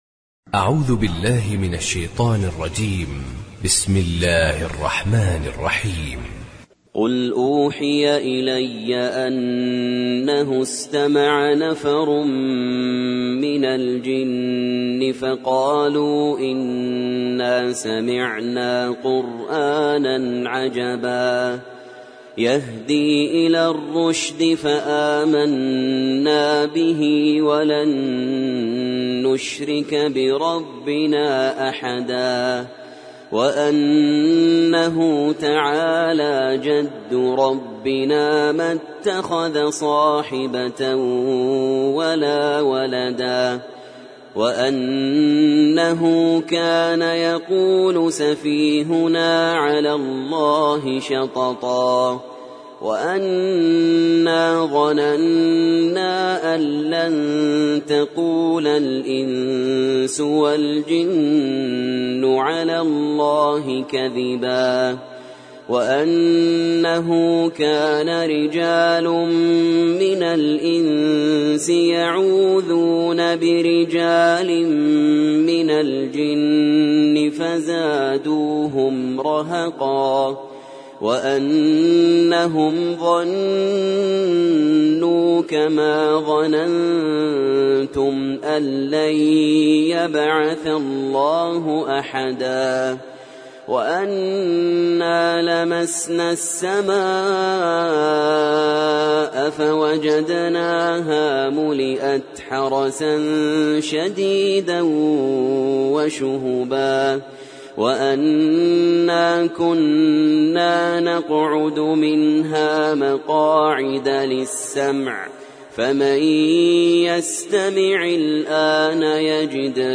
سورة الجن - المصحف المرتل (برواية حفص عن عاصم)
جودة عالية